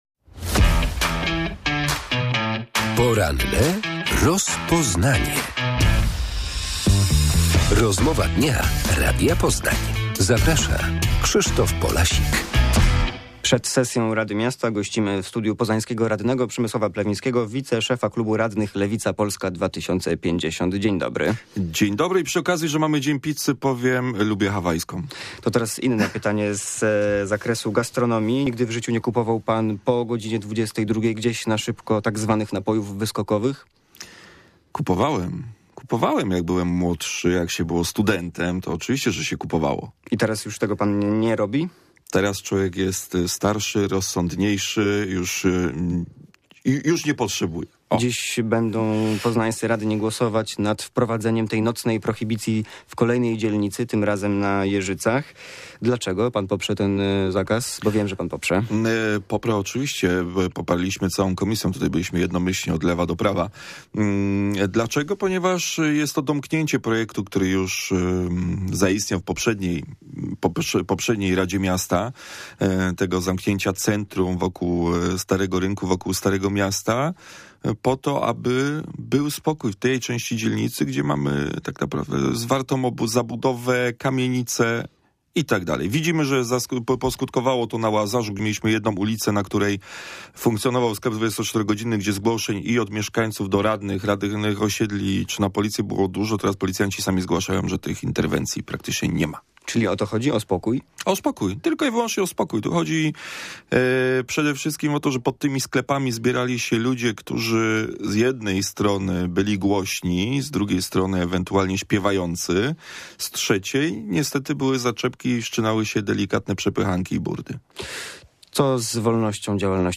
Przed piątkową sesją Rady Miasta Poznania gościem porannej rozmowy jest wiceprzewodniczący klubu radnych Lewica Polska 2050 Przemysław Plewiński. Rozmawiamy między innymi o nocnej prohibicji na Jeżycach i aferze korupcyjnej z koncesjami